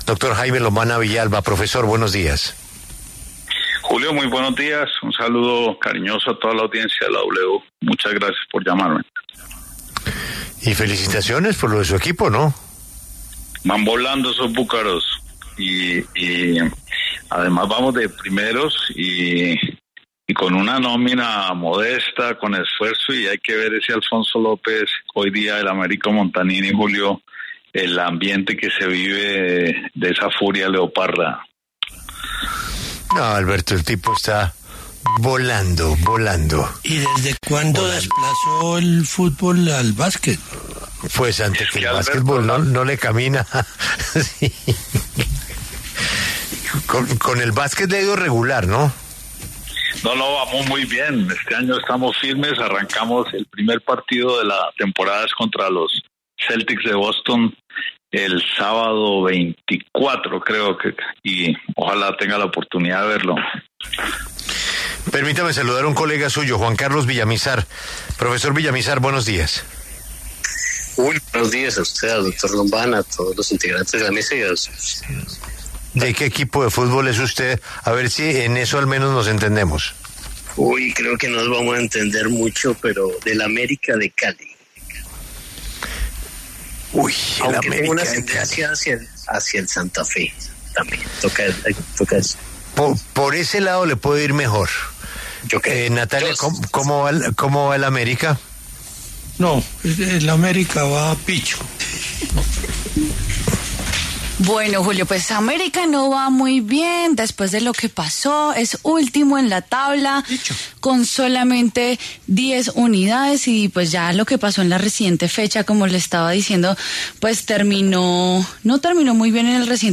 Debate: ¿Una persona puede ser detenida en flagrancia si hace parte de diálogos de paz?